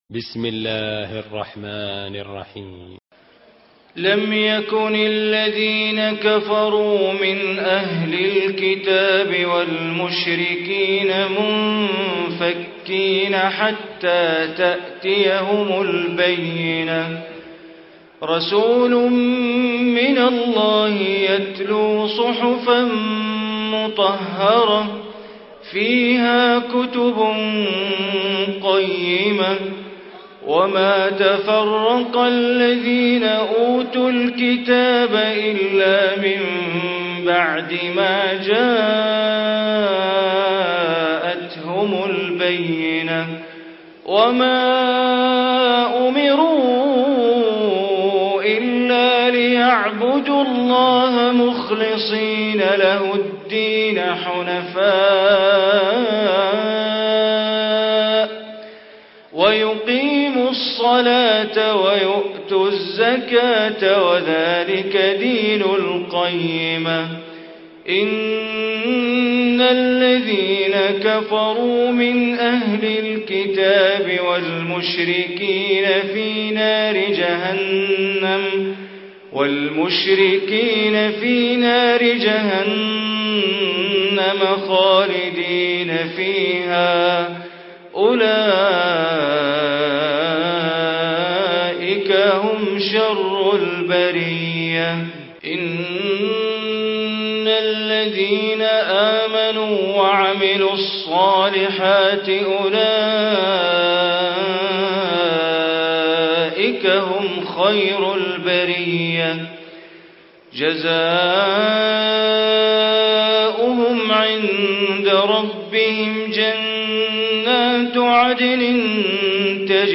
Surah Bayyinah Recitation by Sheikh Bandar Baleela
Surah Al-Bayyinah, listen online mp3 tilawat / recitation in Arabic recited by Imam e Kaaba Sheikh Bandar Baleela.
98-surah-bayyinah.mp3